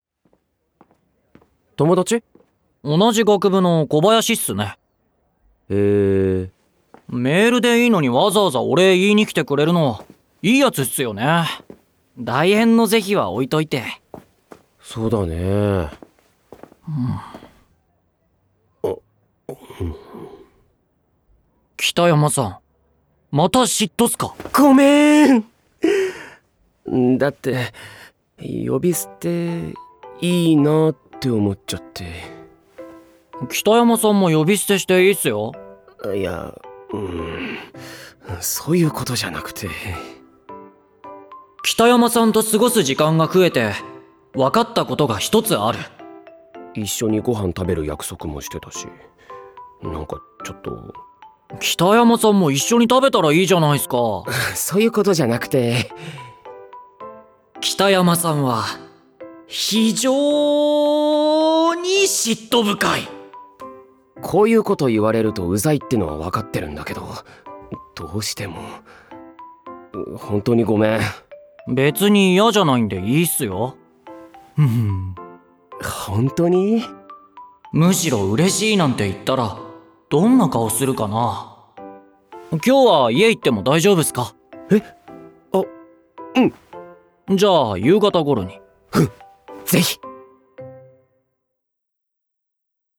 ドラマCD「北山くんと南谷くん2」
出演：広瀬裕也, 西山宏太朗